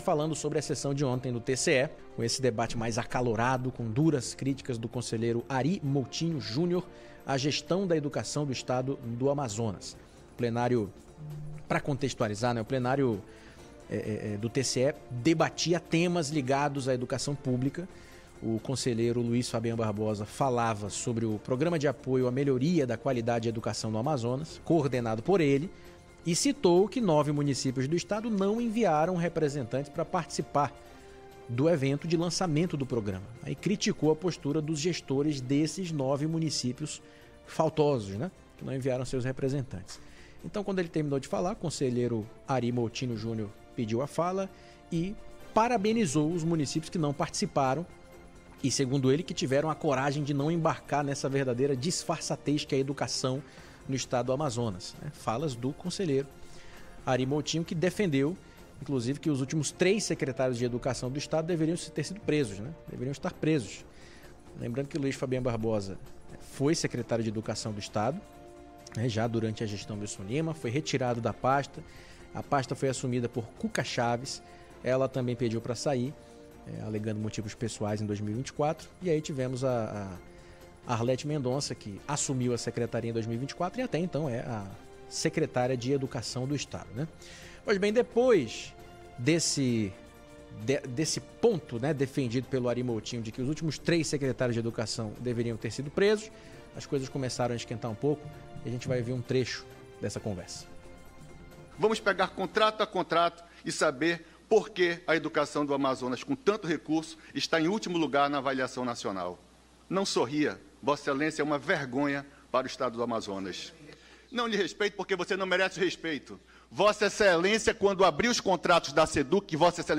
A sessão ordinária do Tribunal de Contas do Amazonas (TCE-AM), realizada nesta segunda-feira (9), teve troca de ofensas entre os conselheiros Ary Moutinho Jr. e Luís Fabian durante debate sobre educação pública, com acusações mútuas e pedidos de investigação.